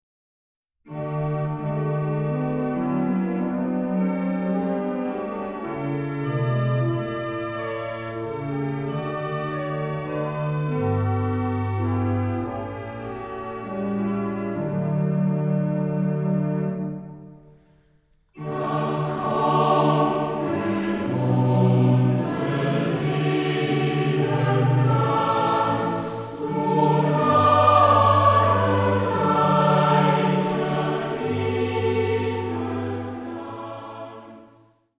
Violine, Englischhorn, Orgel